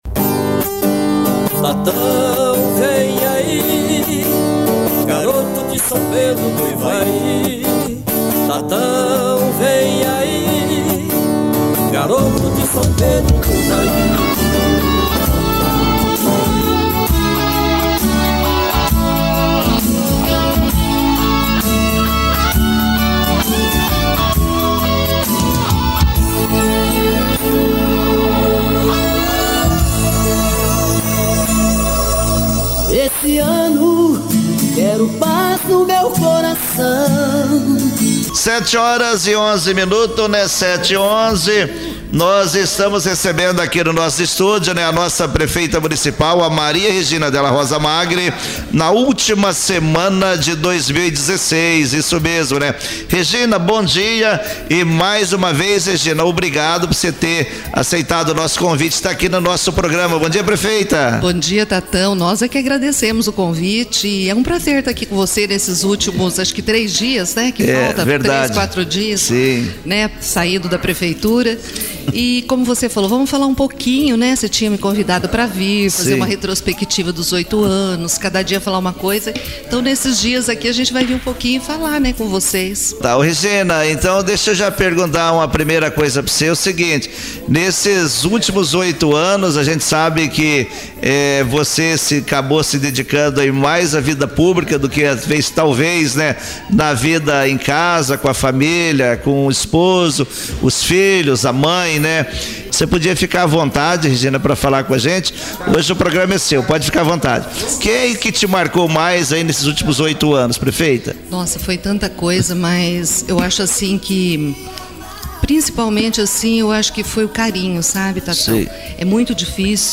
Maria Regina participa de programa de rádio na despedida da prefeitura
Entrevista